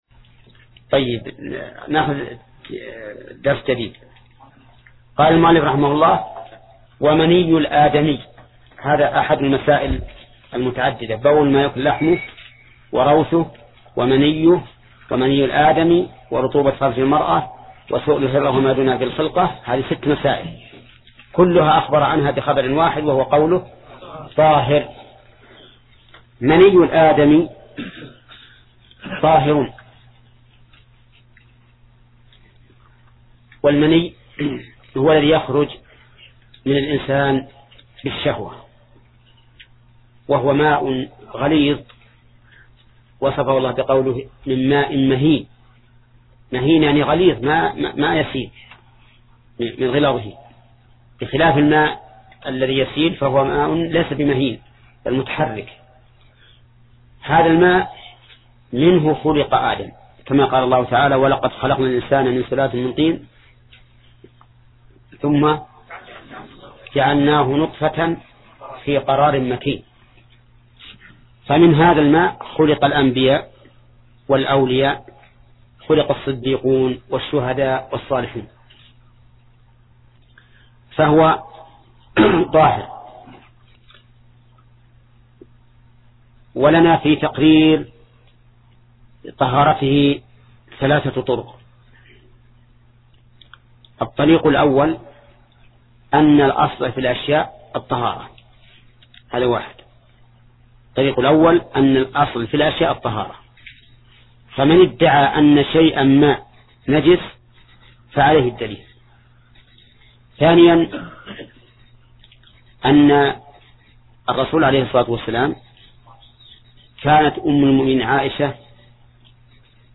درس (23): تتمة باب إزالة النجاسة، وباب الحيض